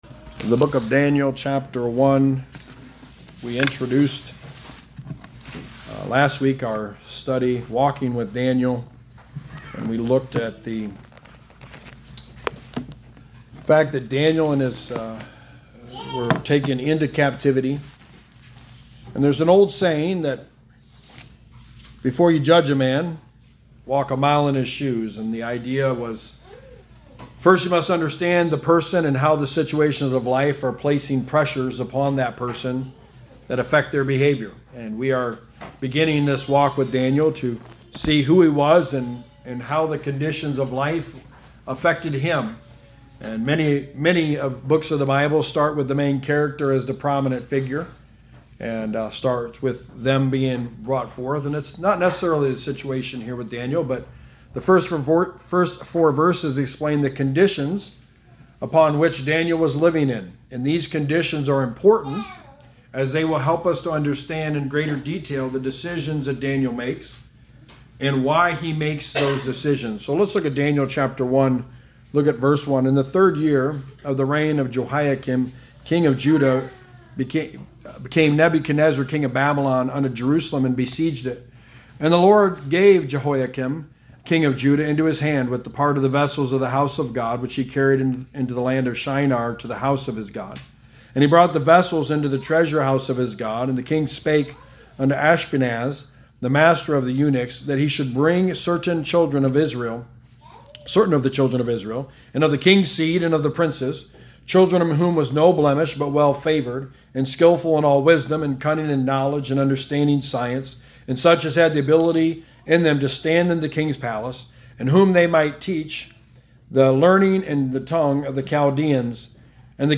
Walking With Daniel – A study of the life of Daniel Sermon #2: The Boldness of Daniel
Passage: Daniel 1:1-4 Service Type: Sunday Morning